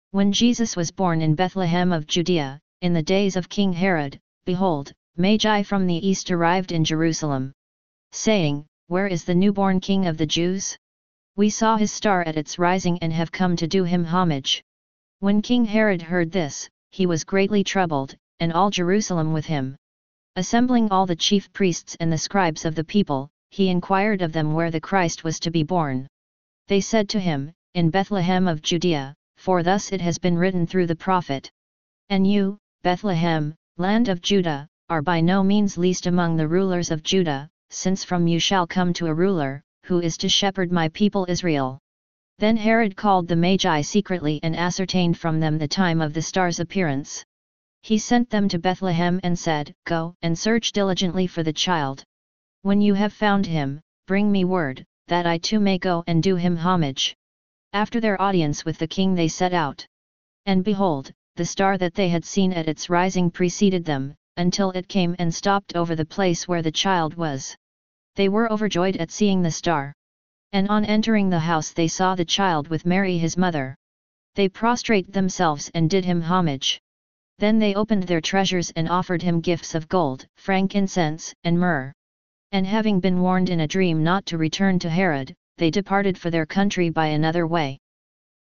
c4982-convert-text-to-speech-mp3.mp3